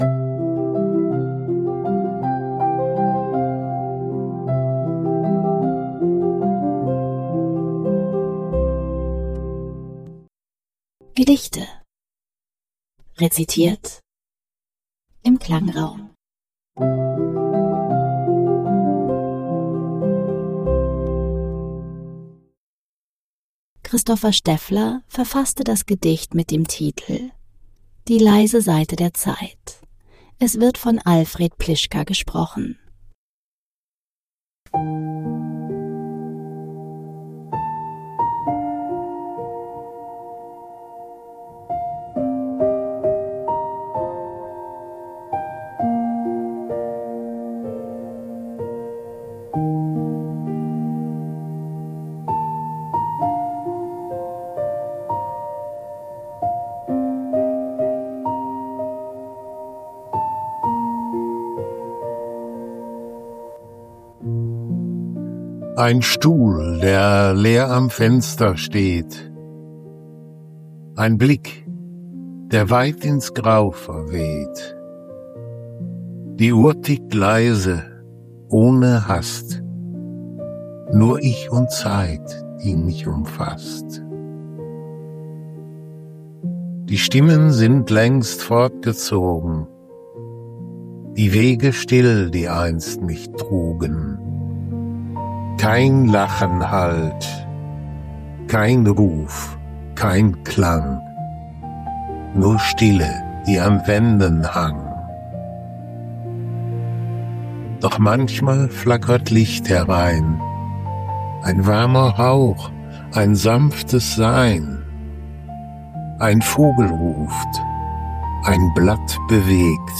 Die Hintergrundmusik wurden mit KI